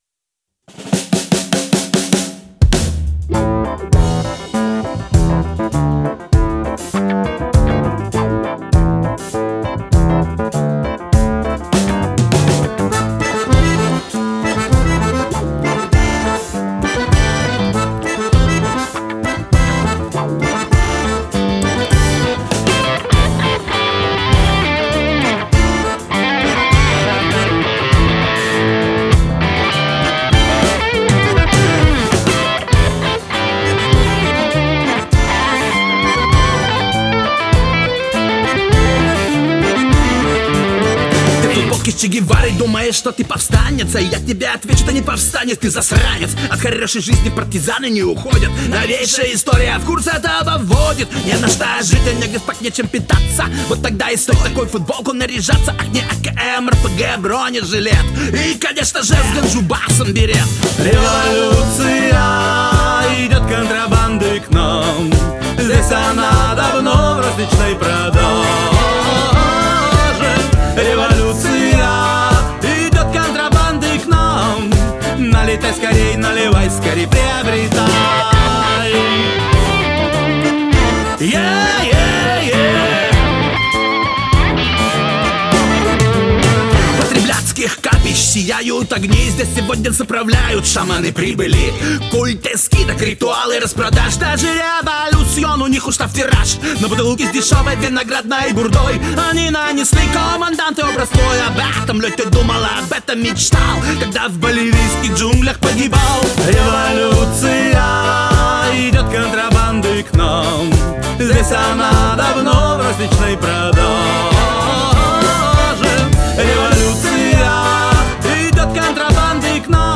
гитары
ударные
бас-гитара
аккордеон